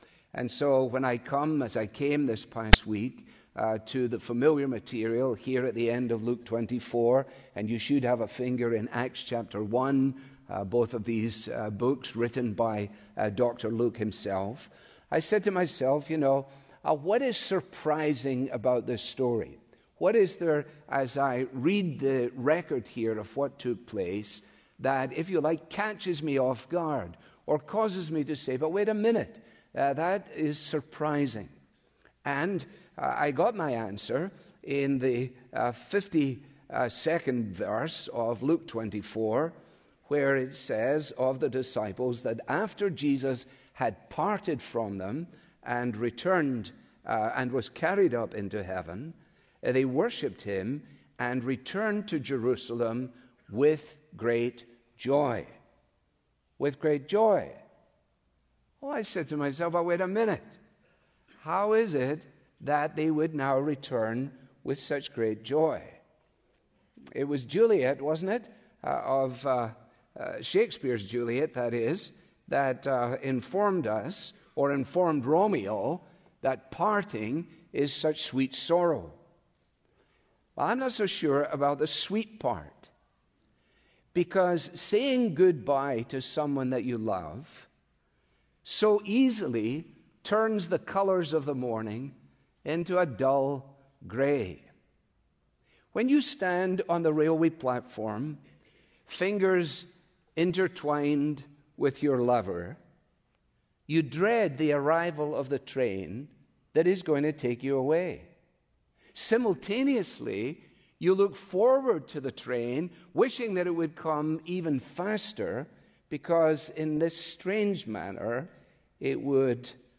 In this example, Alistair Begg frames the passage and his message by developing the contrast between . . .
begg-audio-clip-ascension.m4a